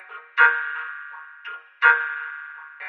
Descarga de Sonidos mp3 Gratis: sintetizador 7.
descargar sonido mp3 sintetizador 7